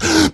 VEC2 Beatbox Mixed
Kopie von VEC2 Beatbox#546F.wav